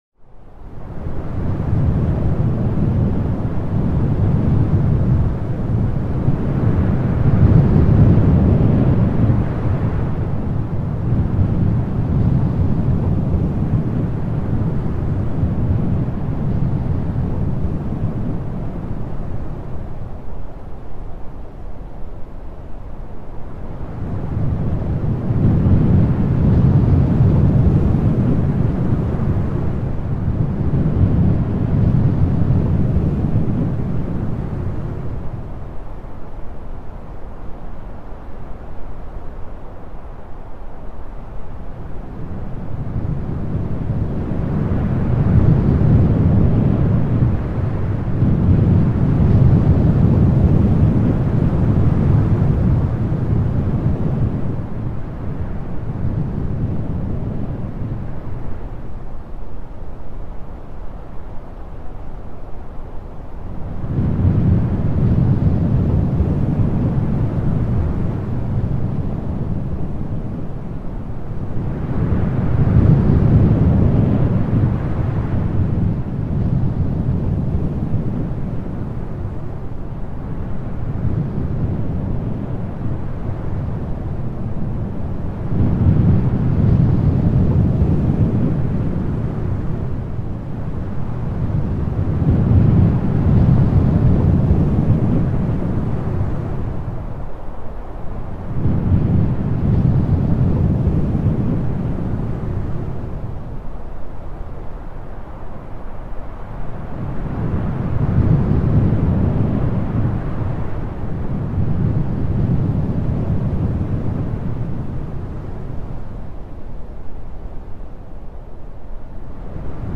Wind Sound
Category: Games   Right: Personal